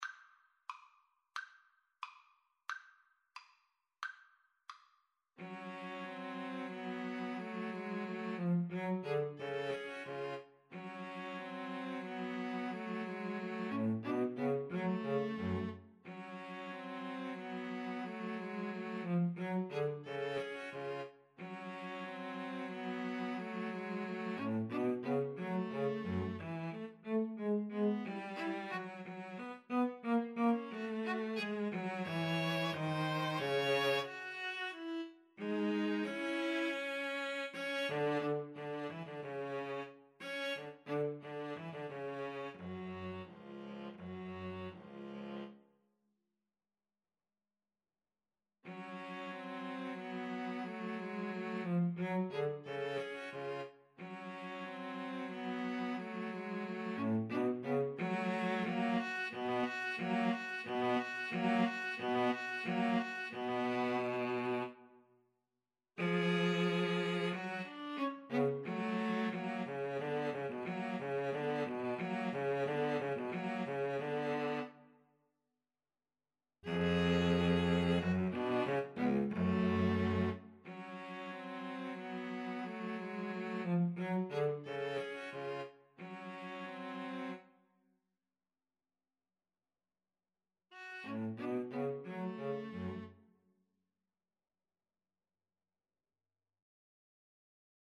Allegretto = 90
Classical (View more Classical String trio Music)